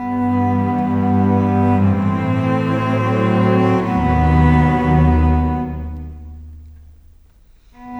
Cinematic 27 Strings 01.wav